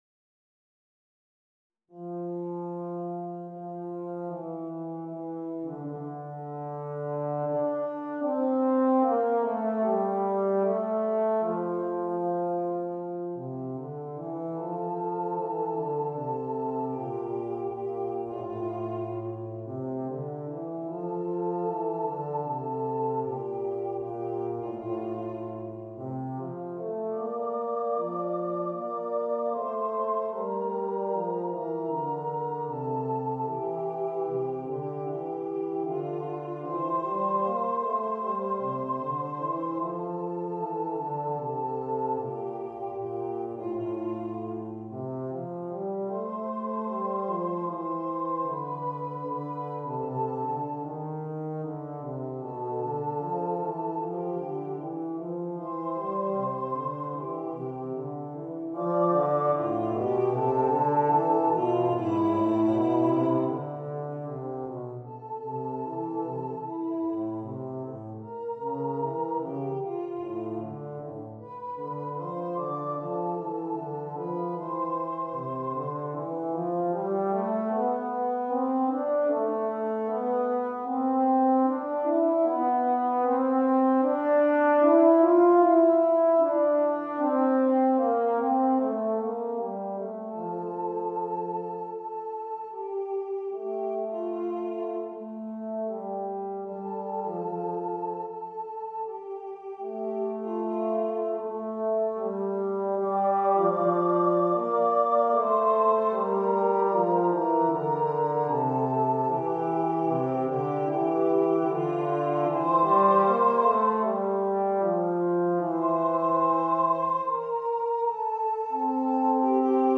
Voicing: Euphonium and Voice